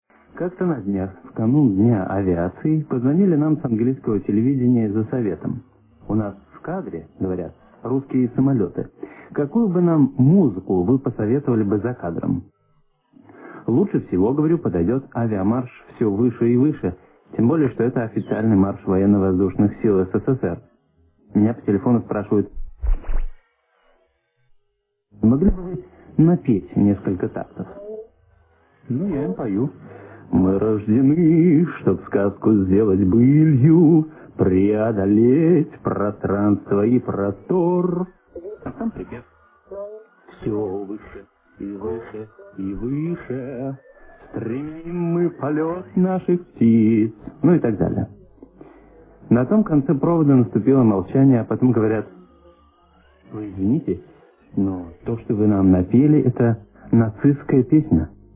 В 1983 году, 26 августа, Сева Новгородцев в рамках передачи «Рок-посев» поведал на Би-би-си о своём поистине удивительном открытии: как оказалось, известный наш «Авиамарш» и по мелодии, и отчасти по тексту совпадает с почти неизвестной у нас песней штурмовиков СА на стыке 20-х и 30-х годов. Послушаем фрагмент из той давней радиопередачи:
В 1983 году, увы, Би-би-си ещё не была у нас уважаемой организацией, поэтому звук тут не очень хороший.